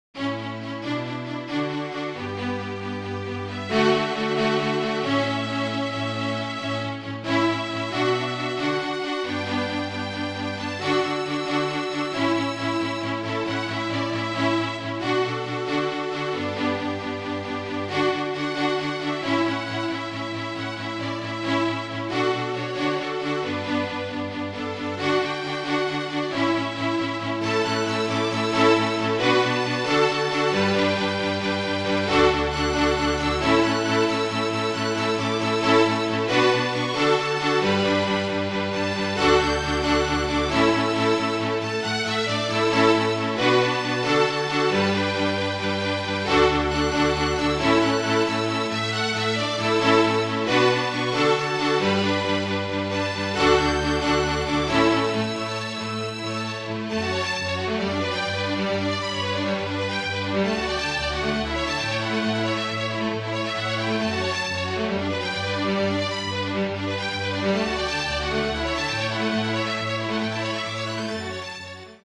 FLUTE TRIO
Flute, Violin and Cello (or Two Violins and Cello)